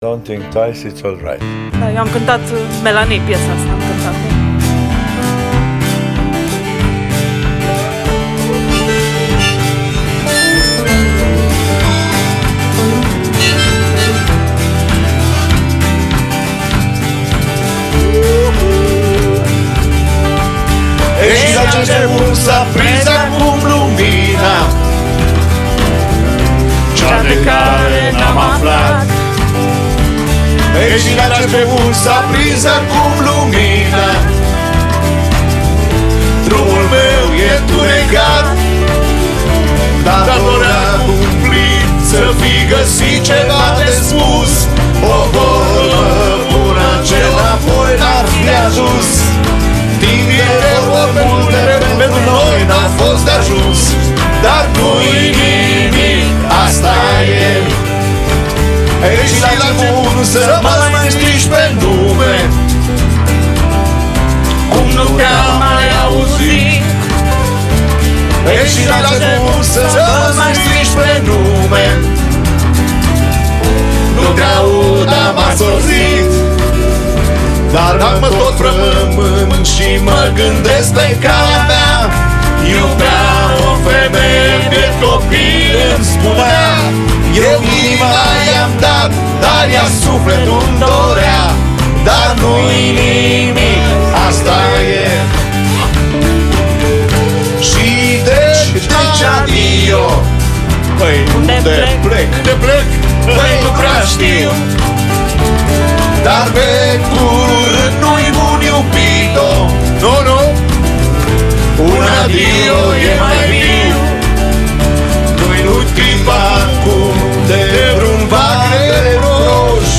Pian
Chitara
Percutie